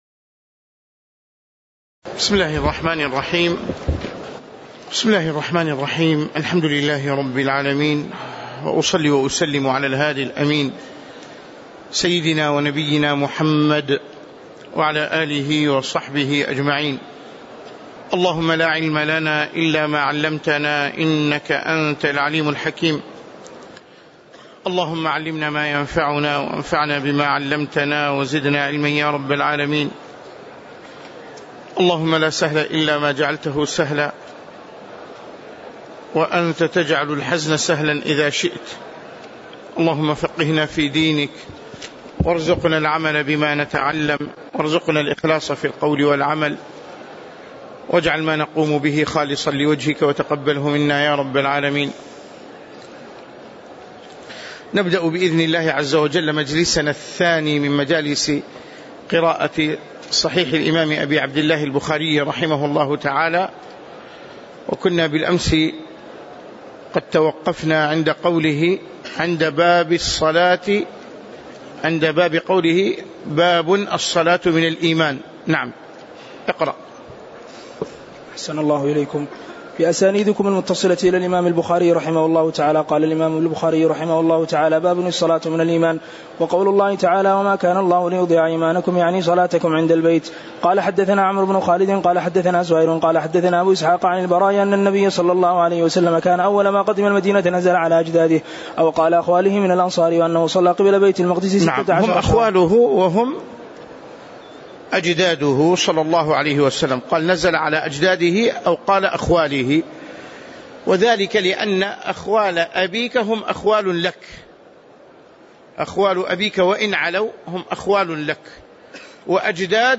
تاريخ النشر ٢ محرم ١٤٣٨ هـ المكان: المسجد النبوي الشيخ